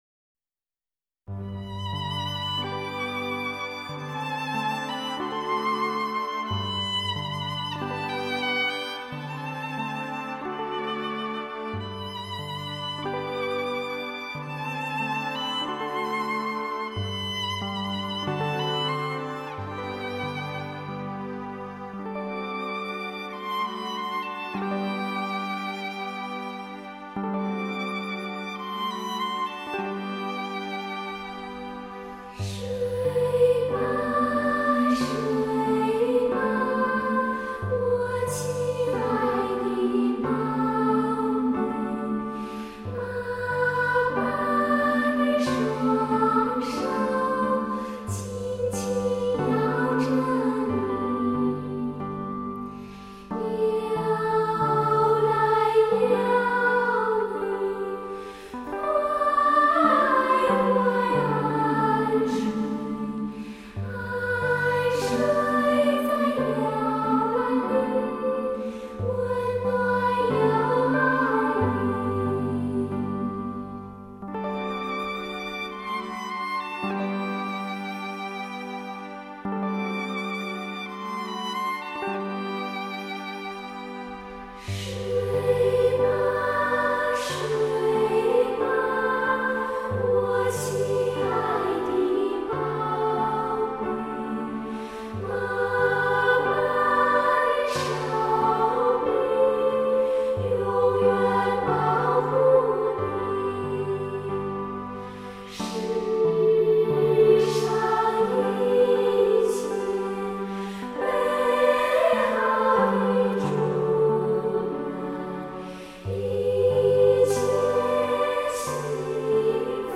民族音乐
这张专辑，十二首全部来自世界各地的民谣，献给能生活在家园快乐幸福的人们，让他们一起感激并美丽着……